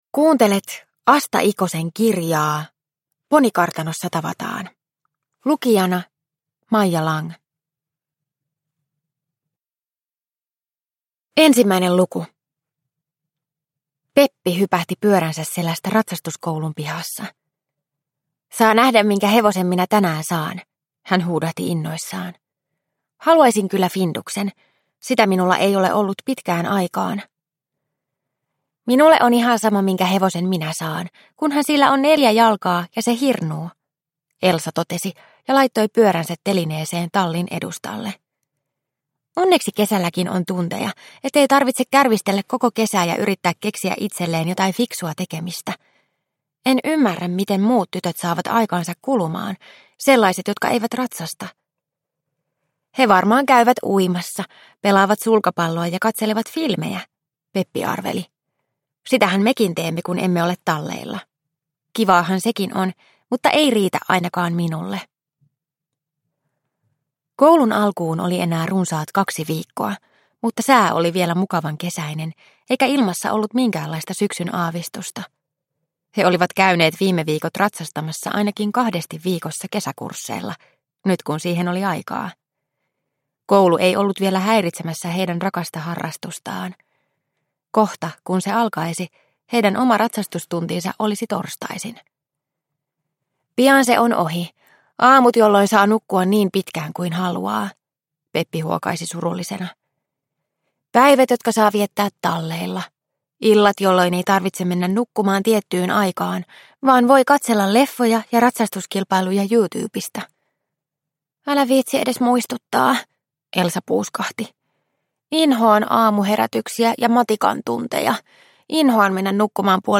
Ponikartanossa tavataan – Ljudbok – Laddas ner